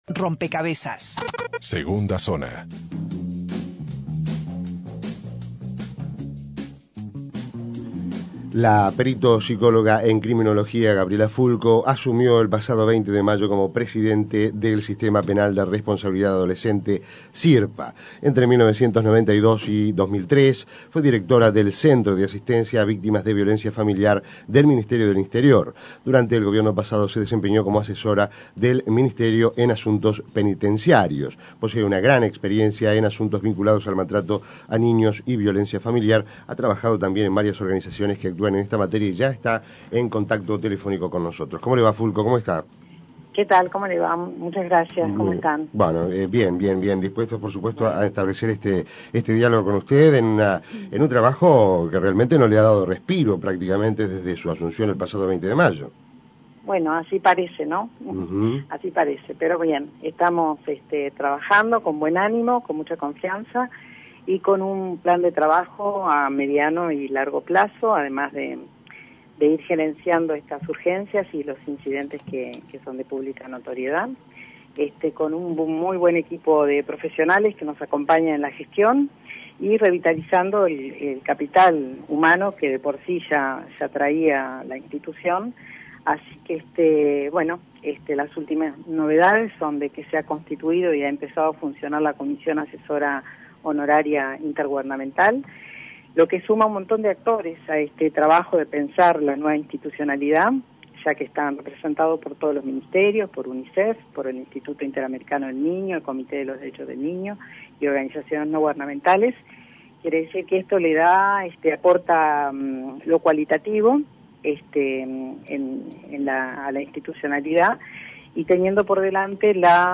La presidenta del Sistema Penal de Responsabilidad Adolescente (Sirpa), Gabriela Fulco, dijo a Rompkbzas se debe "recimentar" el sistema y para ello es necesario tomar varias medidas como cambiar la forma de seleccionar a los funcionarios que trabajan en los centros de privación de libertad y crear una escuela destinada a la formación de estos educadores, una tarea que requiere "una alta especialización".